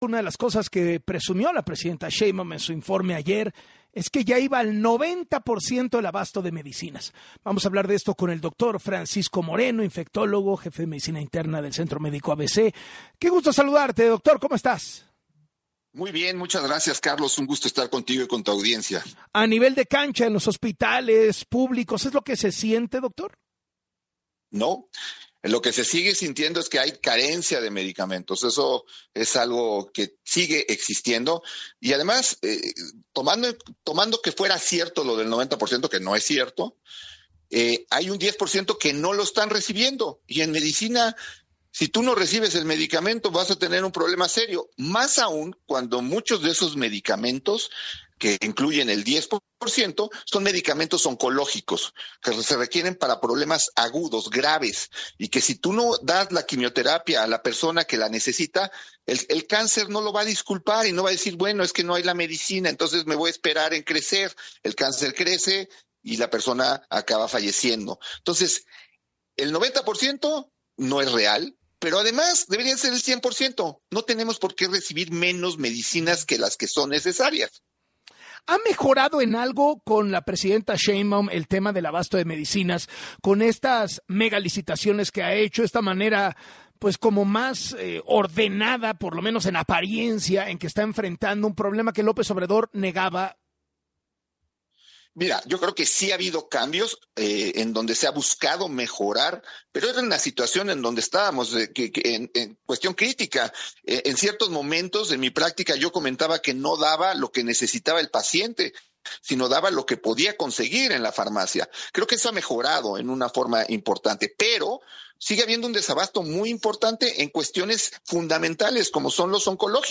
En entrevista con Carlos Loret de Mola